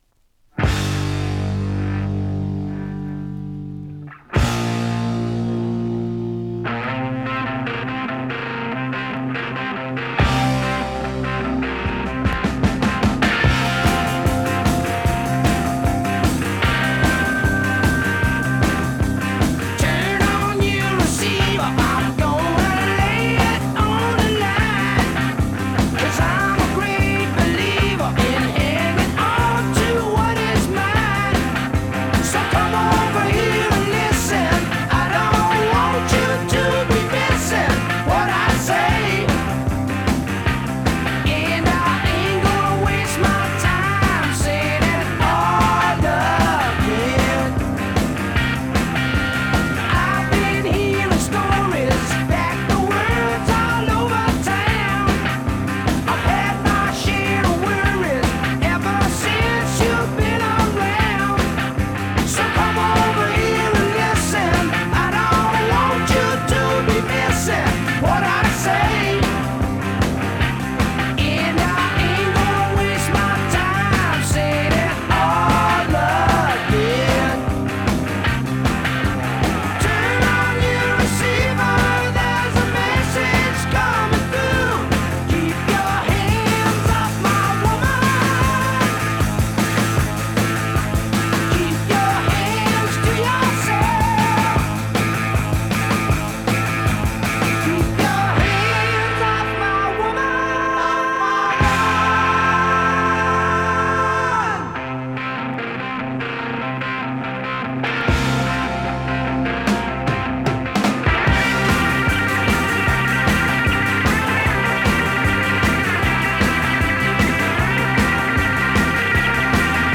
Из FLAC